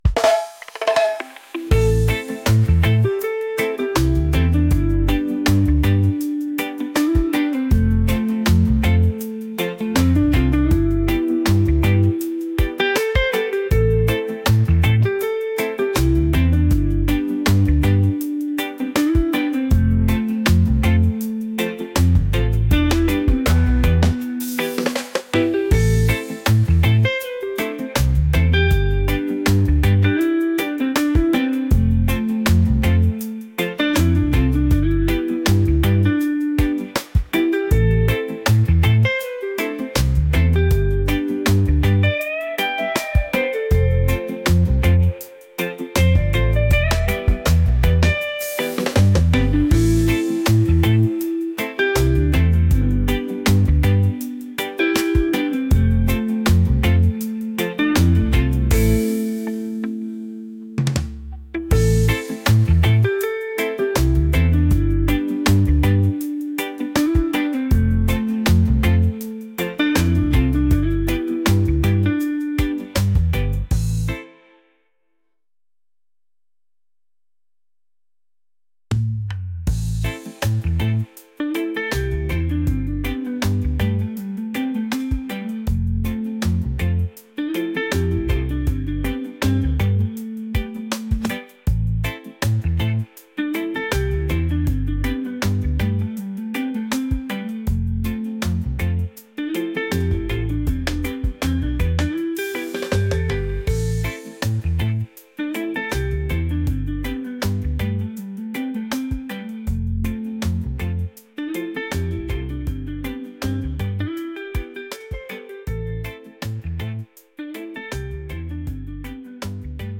laid-back | reggae | soulful